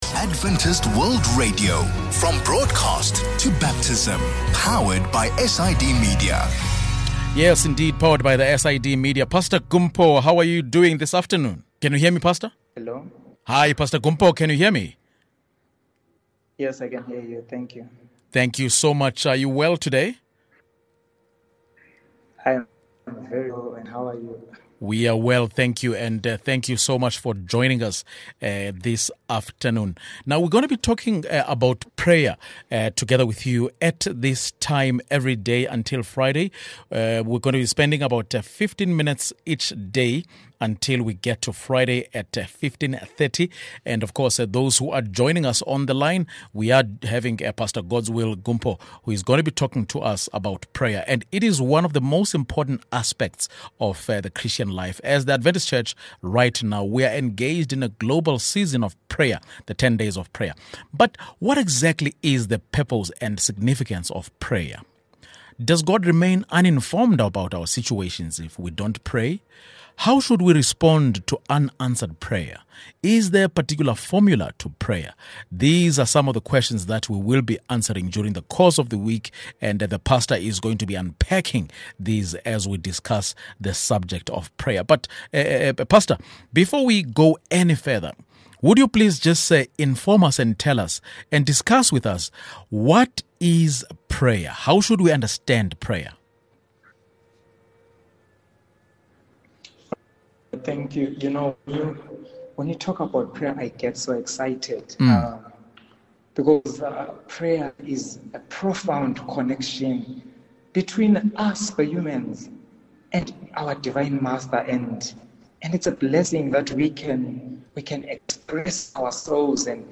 Prayer is one of the most important privileges of the Christian life. In this conversation, we learn why that is, and also touch on the purpose and power of group prayer.